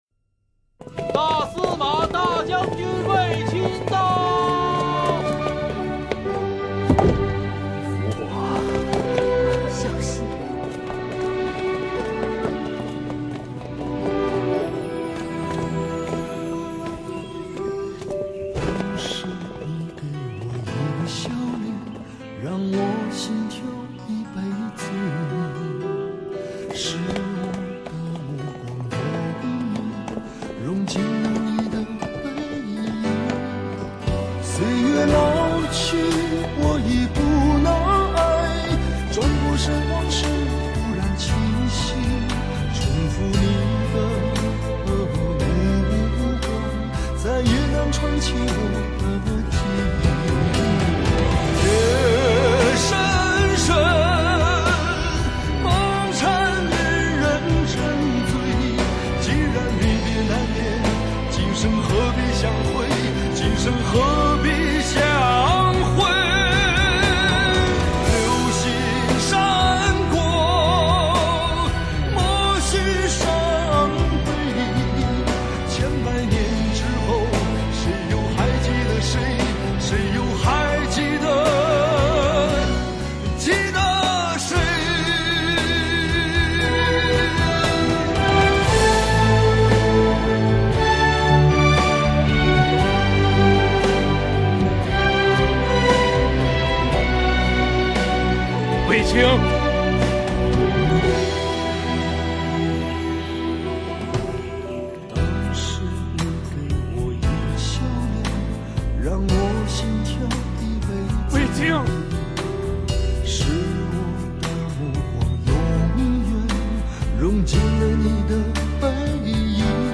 Ps：在线试听为压缩音质节选，体验无损音质请下载完整版 当时你给我一个笑脸，让我心跳一辈子。